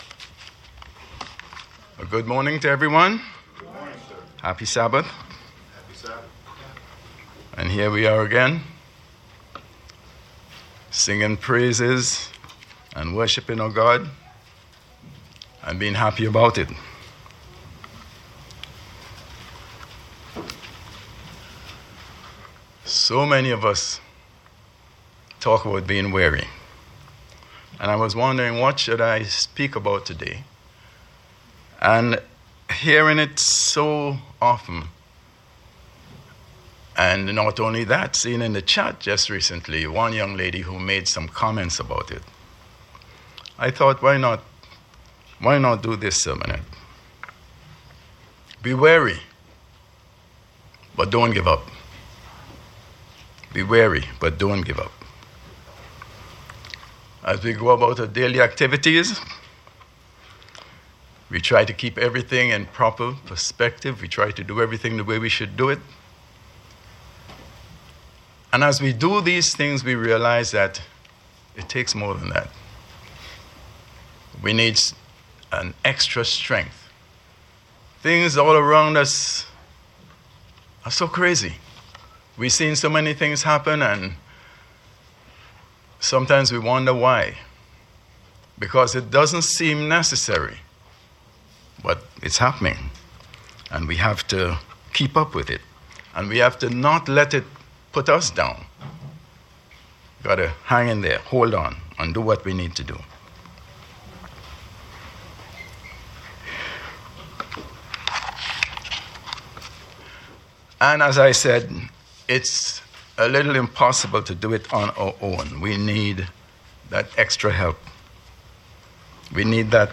Sermons
Given in New York City, NY